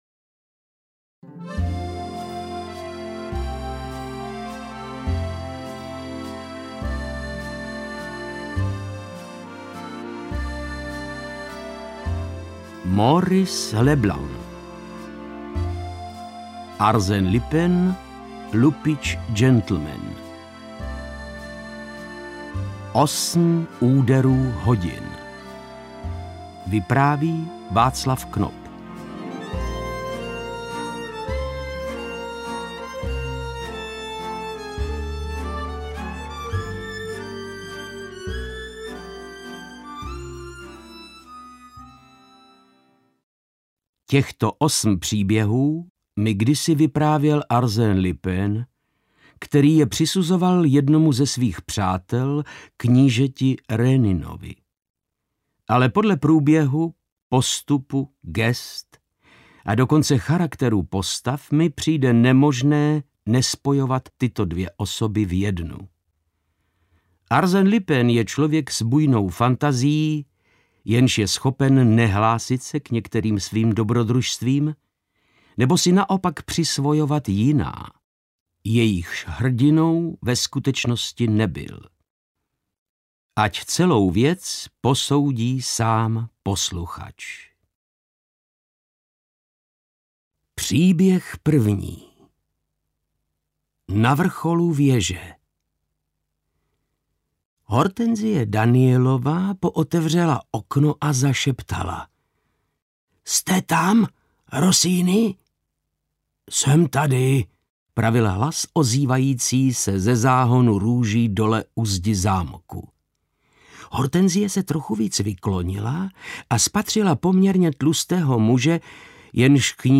Osm úderů hodin audiokniha
Ukázka z knihy
• InterpretVáclav Knop